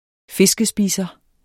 Udtale [ ˈfesgəˌsbiːsʌ ]